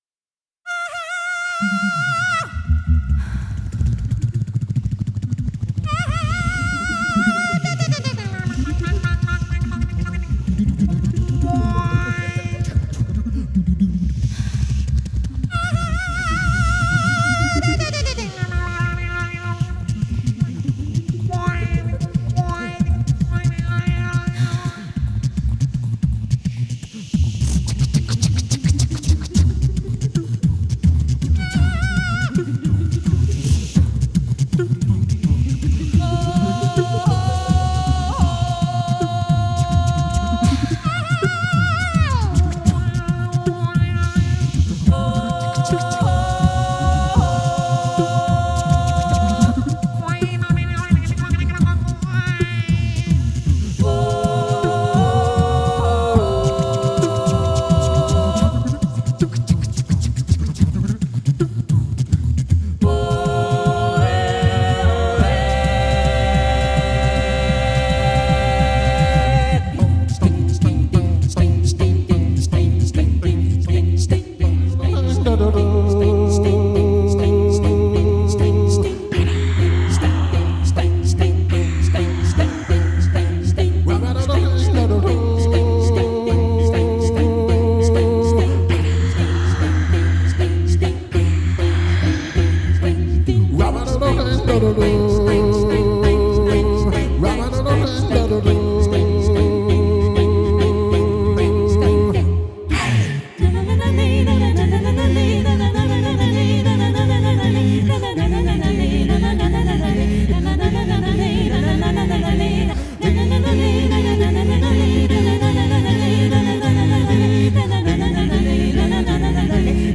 жывая вэрсія кампазыцыі